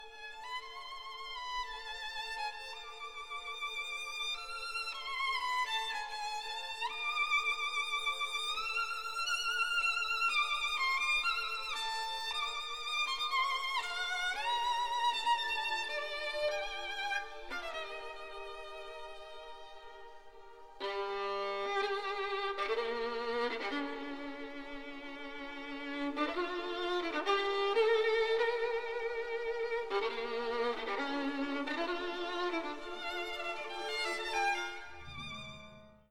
Eldbjørg Hemsing, violon
Un premier mouvement rhapsodique, une poignante cantilène et un rondo bondissant précèdent la Symphonie « Rhénane » de Schumann.
Sibelieus-violon-concerto.mp3